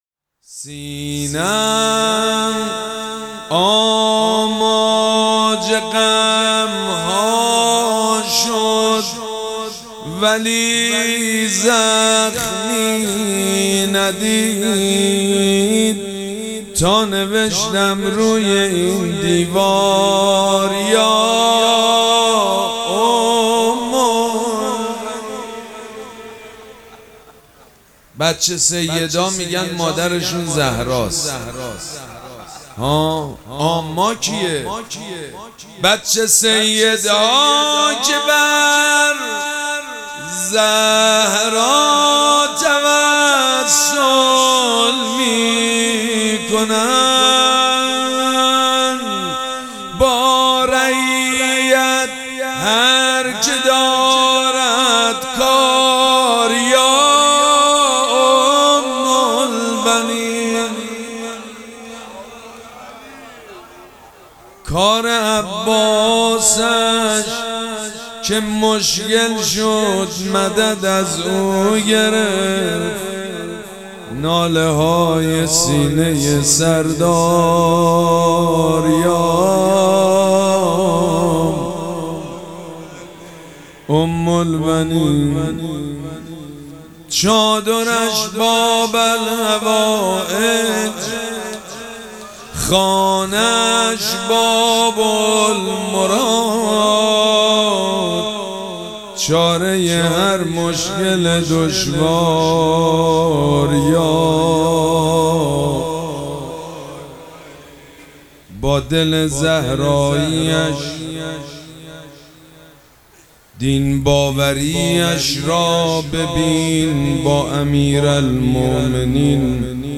شب چهارم مراسم عزاداری اربعین حسینی ۱۴۴۷
روضه
مداح
حاج سید مجید بنی فاطمه